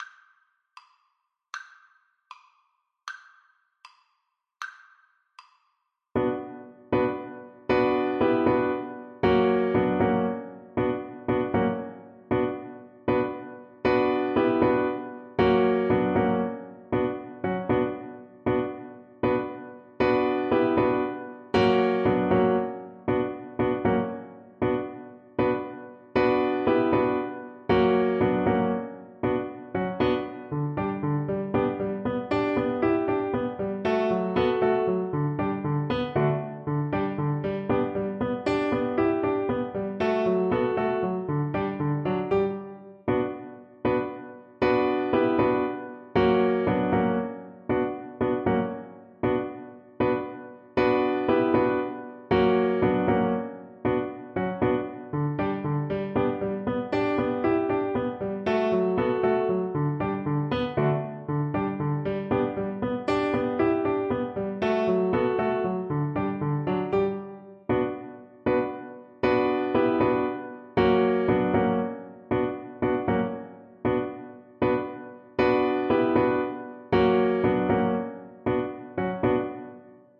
6/8 (View more 6/8 Music)
Classical (View more Classical Violin Music)